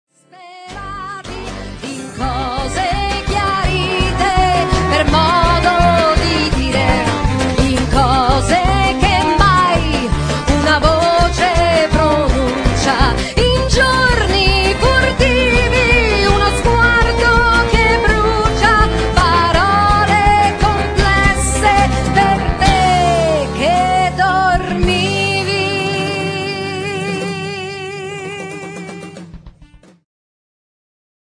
ジャンル Progressive
アコースティック
ローマ出身のアコースティック楽器と女性ヴォーカルによるグループ。